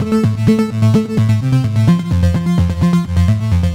Index of /musicradar/french-house-chillout-samples/128bpm/Instruments
FHC_Arp B_128-A.wav